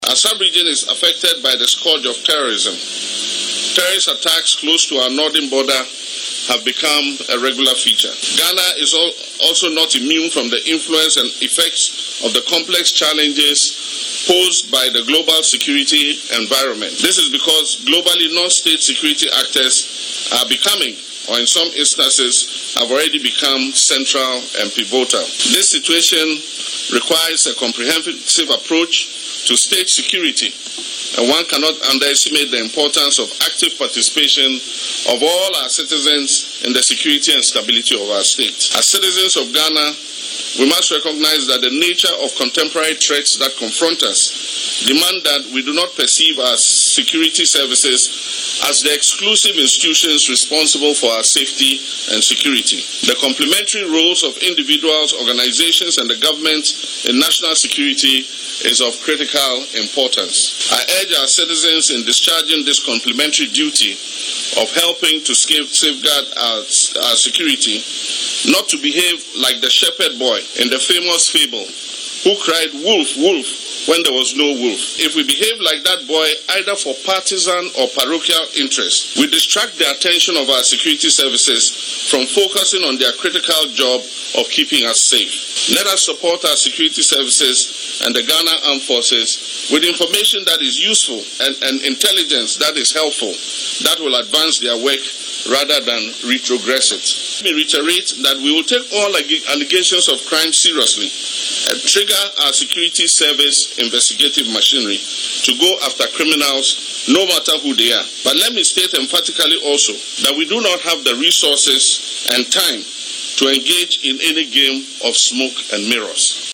Speaking at a graduation ceremony at the Ghana Military Academy on Friday, April 11, the President said the Asantehene had assured him of his commitment to reconvene stakeholders and continue the peace dialogue upon his return from a brief visit abroad.